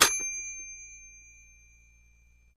Baby Toy Bell, Single Ring